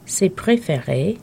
Click each phrase to hear the pronunciation.